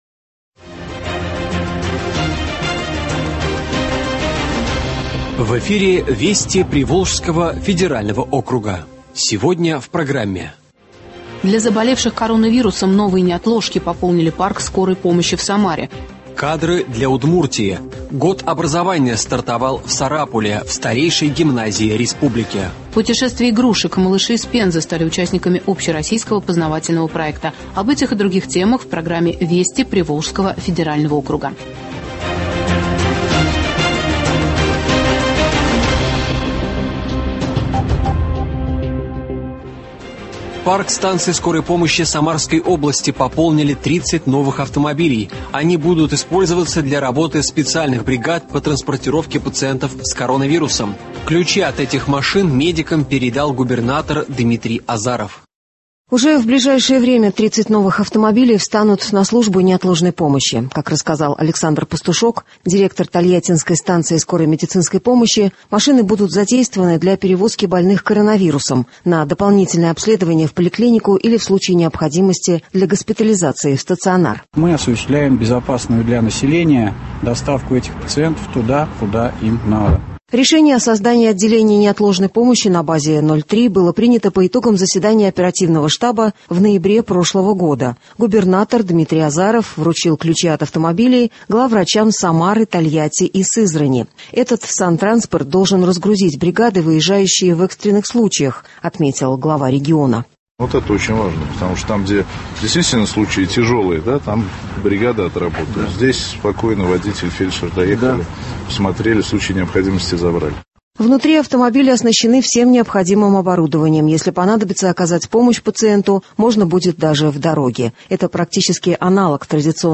Радиообзор событий недели в регионах ПВО.